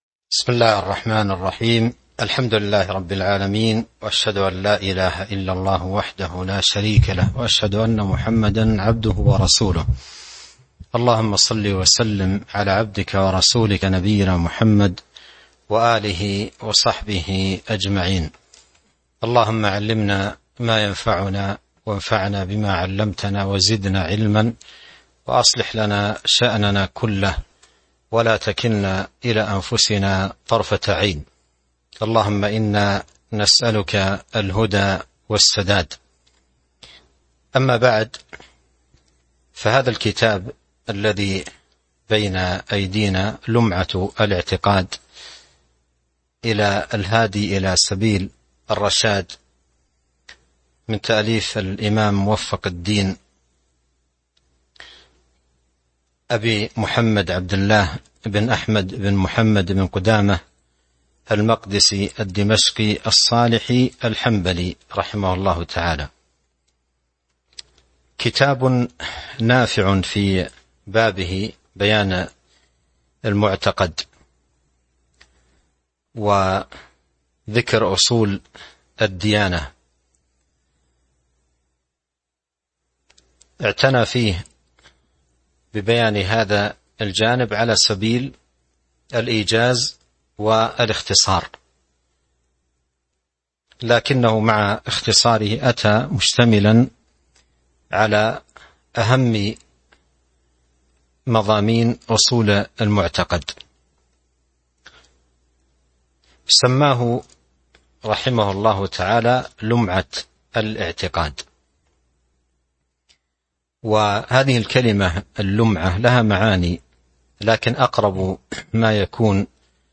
تاريخ النشر ١٧ ذو الحجة ١٤٤٢ هـ المكان: المسجد النبوي الشيخ: فضيلة الشيخ عبد الرزاق بن عبد المحسن البدر فضيلة الشيخ عبد الرزاق بن عبد المحسن البدر المقدمة (01)